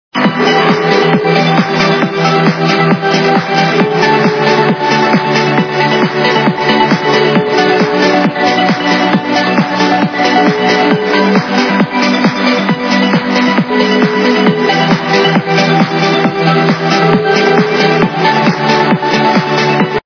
западная эстрада